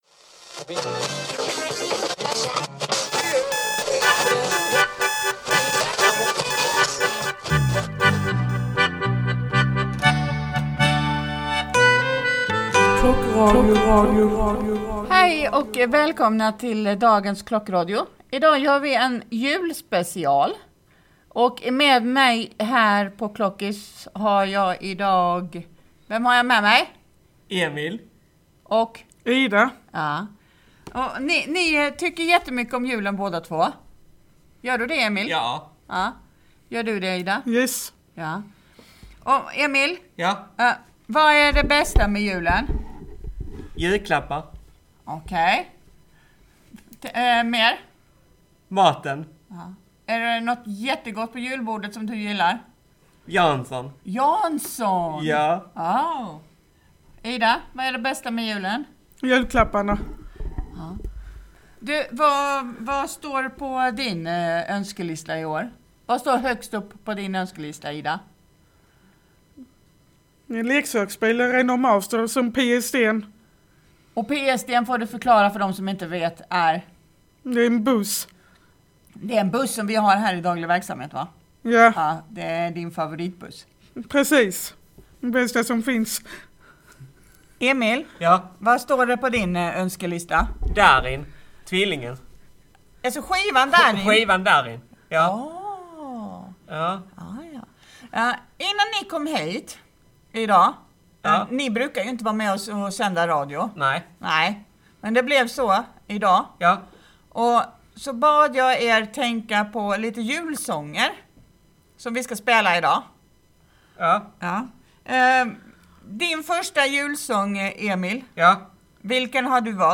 Idag sänder vi för sista gången i år. Vi lyssnar på våra julfavoriter och pratar om jultraditioner.